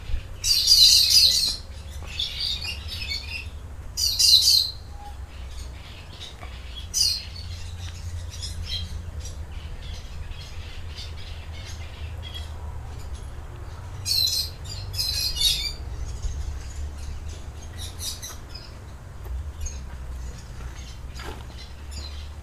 Catita Tirica (Brotogeris tirica)
Nombre en inglés: Plain Parakeet
Fase de la vida: Adulto
País: Brasil
Condición: Silvestre
Certeza: Fotografiada, Vocalización Grabada
Periquito-Rico.mp3